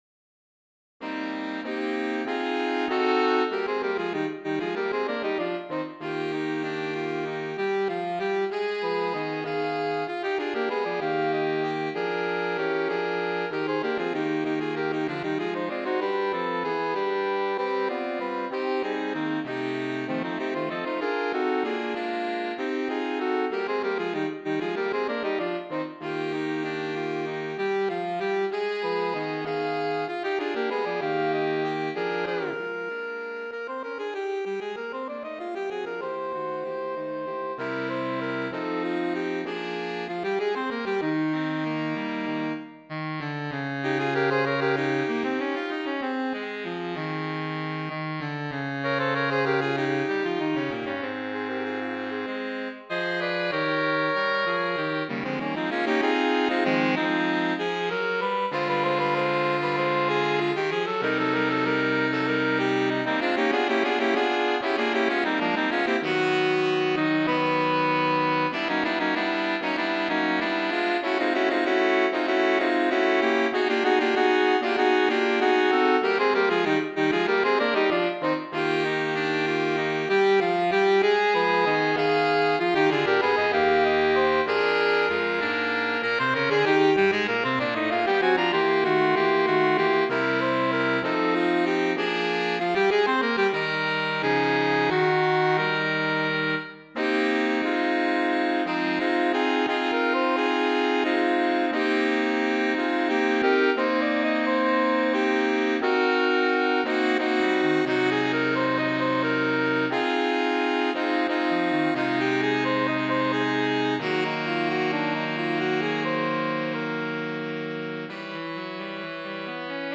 Voicing: Saxophone Quartet (SATB)